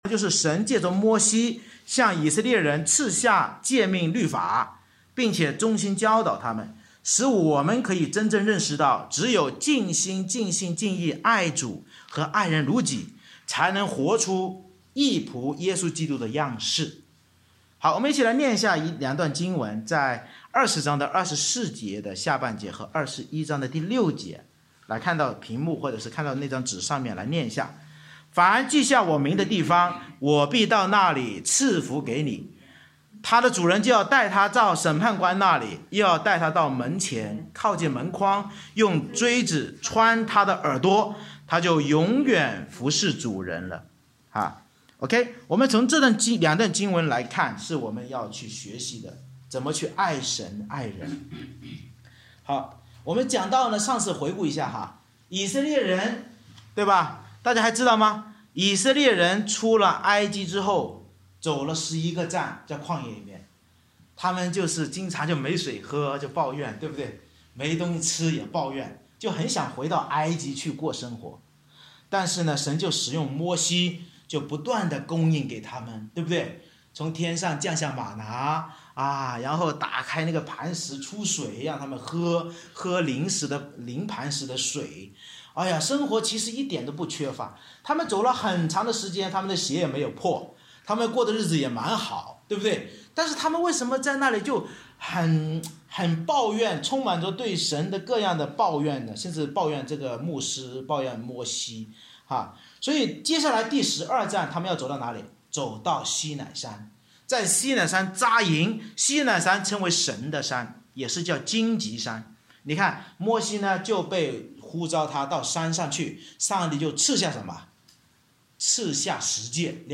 出埃及记20：22-21：11 Service Type: 主日崇拜 Bible Text